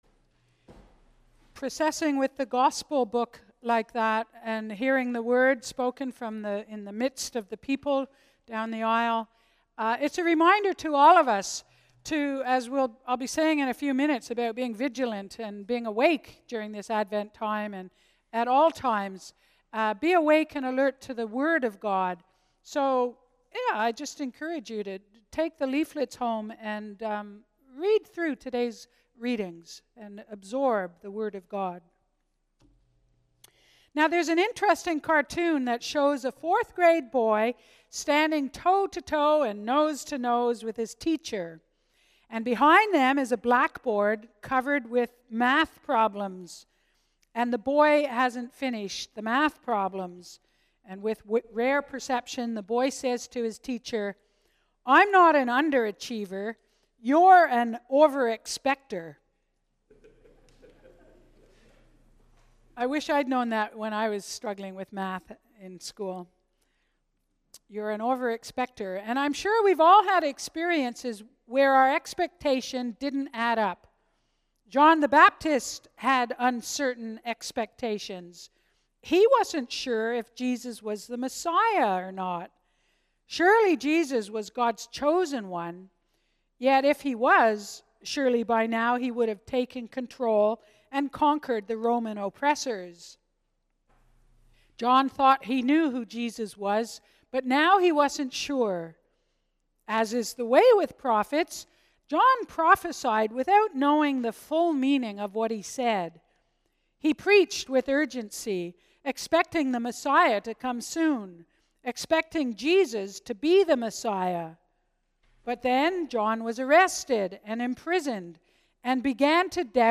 Sermons | Parish of the Valley